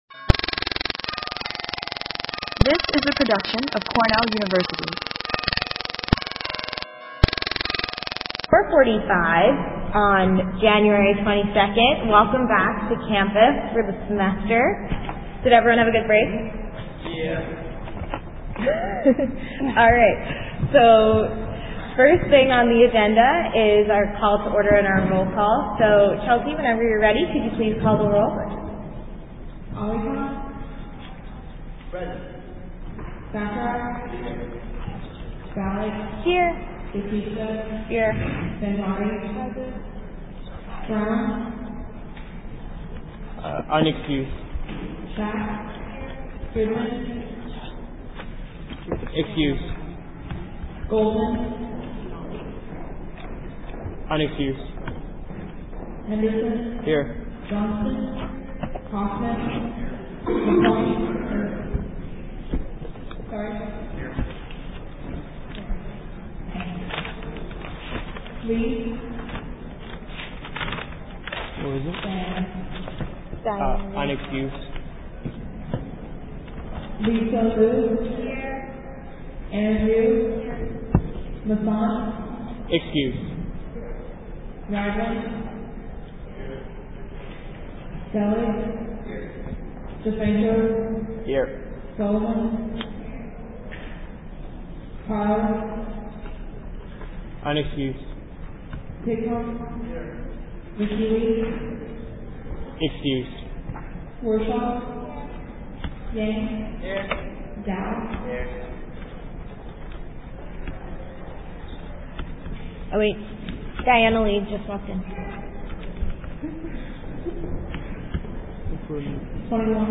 January 22, 2015 Meeting